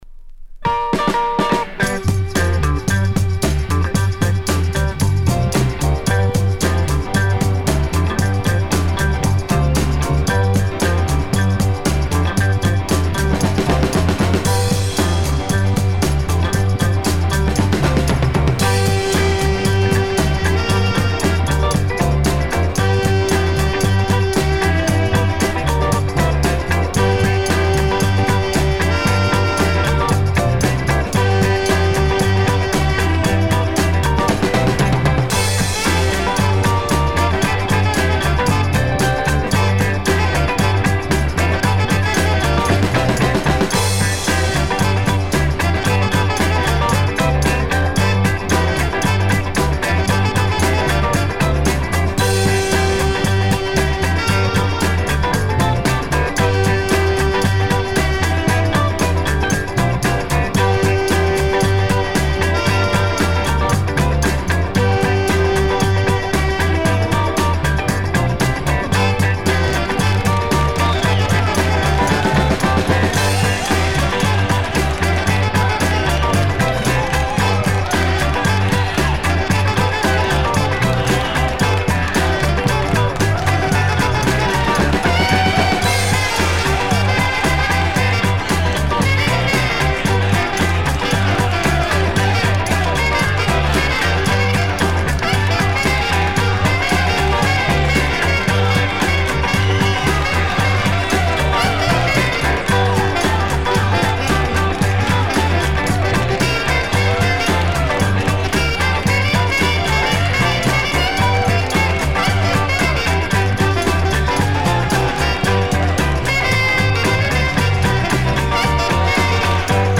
Funk ソウル・ファンクグループ
Side A→Side B(2:50～) 試聴はここをクリック ※実物の試聴音源を再生状態の目安にお役立てください。